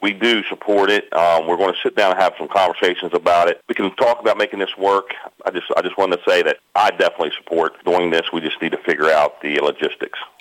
City Council member Brian Lepley called in to WCBC to say he supports the event and finding a way to make it happen…